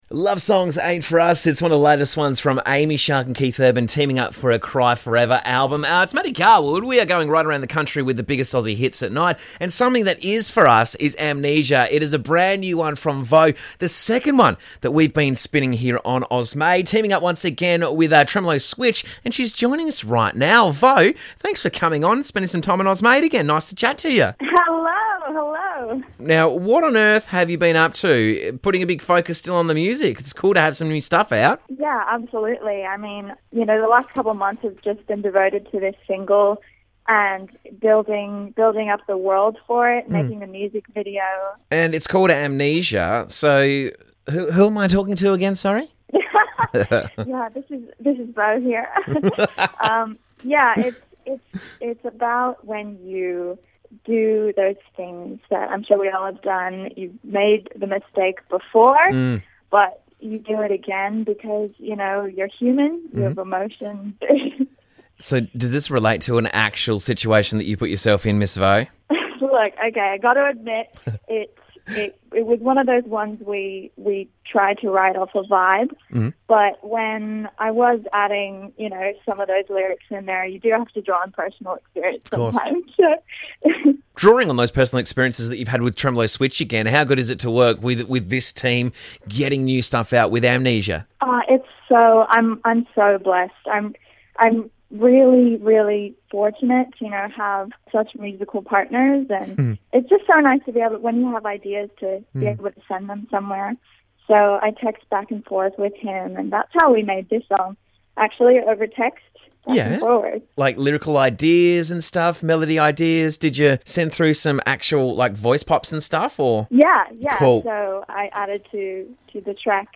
eclectic world pop songs